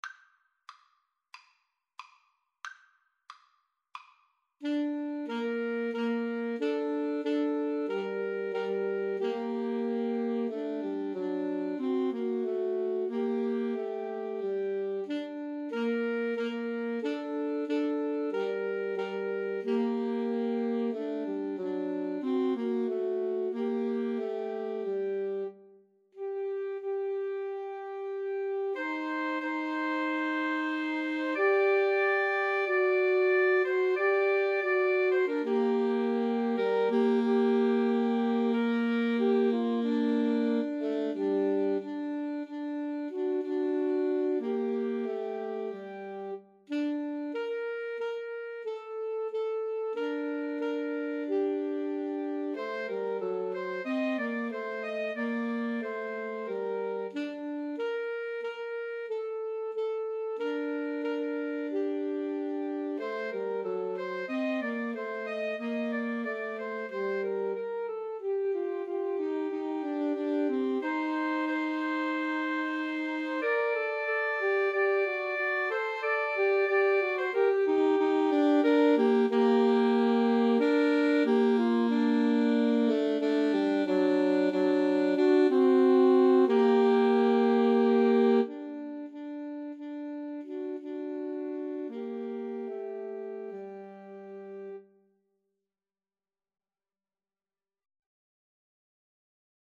Alto Sax Trio version
Andante cantabile ( = c. 92)